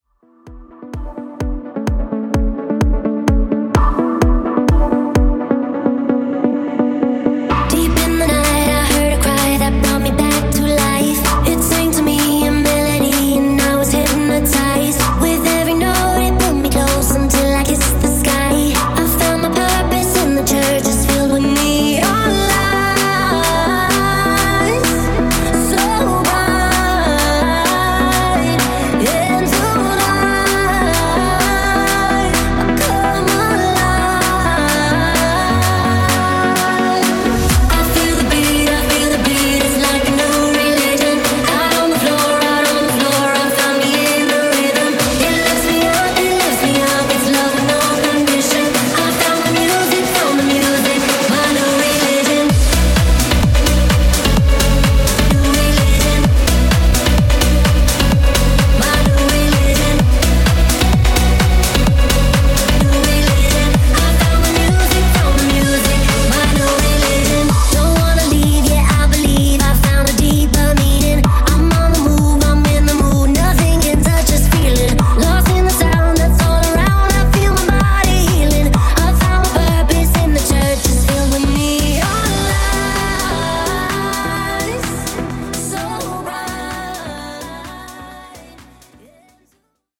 No. 54 in DANCE
Genre: Version: BPM: 128 Time: 3:31